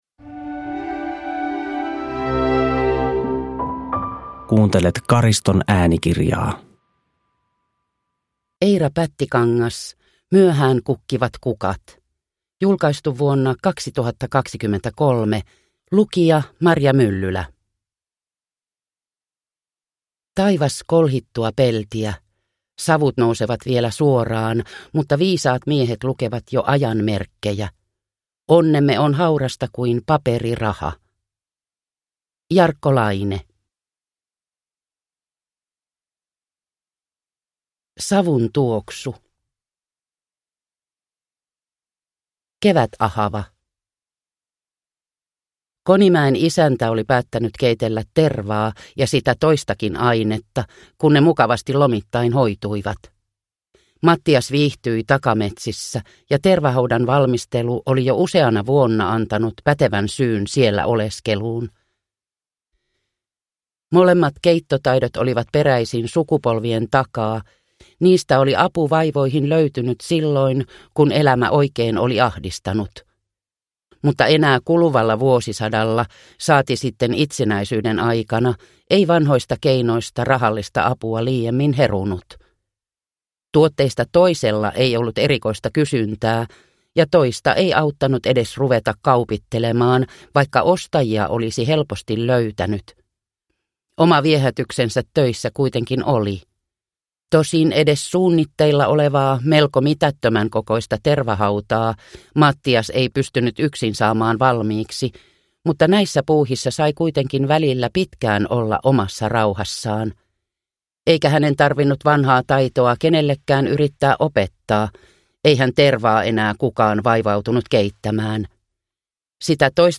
Myöhään kukkivat kukat (ljudbok) av Eira Pättikangas